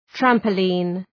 Προφορά
{,træmpə’lın}